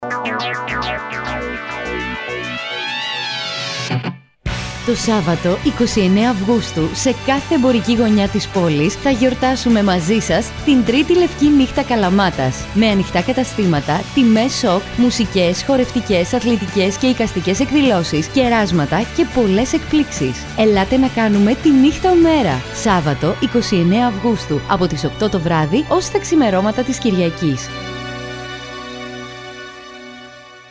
Ραδιοφωνικό σποτ
White_Night_2015_PROMO_RADIO_spot.mp3